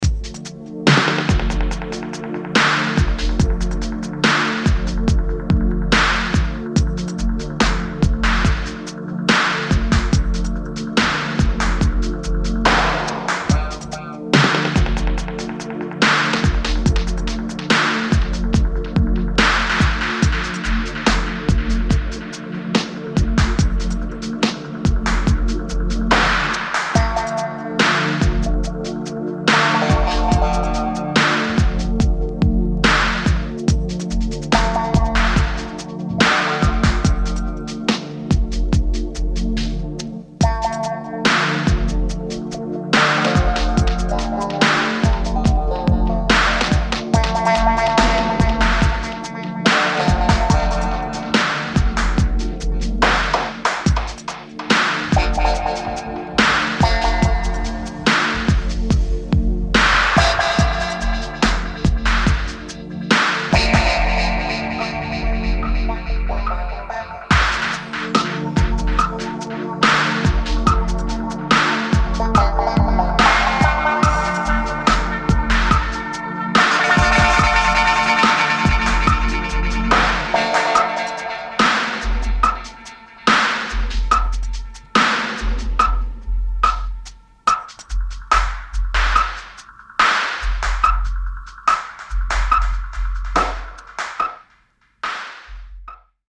Deepstep. D&B. Breaks